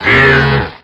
Cri de Crapustule dans Pokémon X et Y.